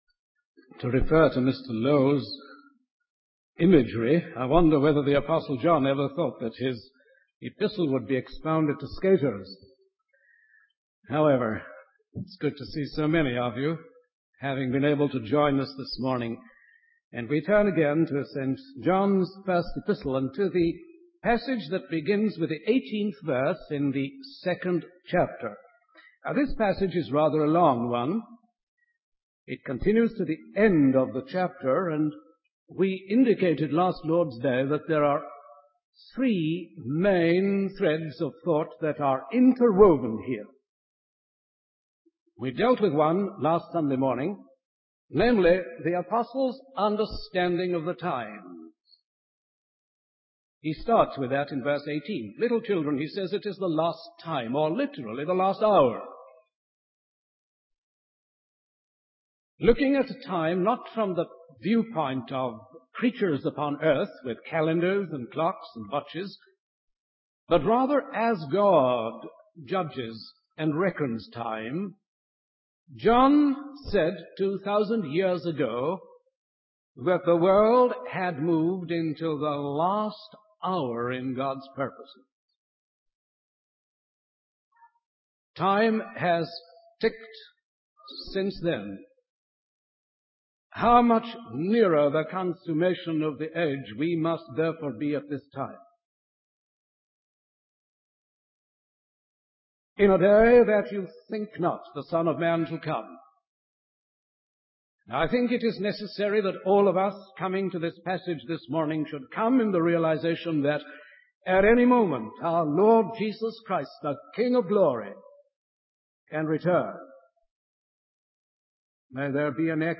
In this sermon, the speaker discusses the concept of departure and its significance in the lives of believers.